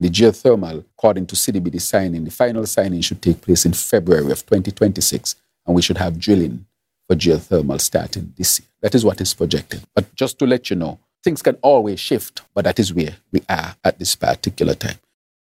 Prime Minister, Dr. Terrance Drew, speaking about the geothermal project on Nevis.